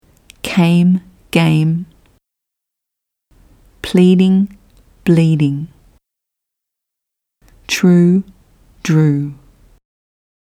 • Most Australian English speakers aspirate the sounds [p, t, k] at the beginning of words – this means a small ‘puff’ of air is released with it, like producing a small [h].
(Think: ‘c[h]ame’, ‘p[h]leading’, ‘t[h]rue’)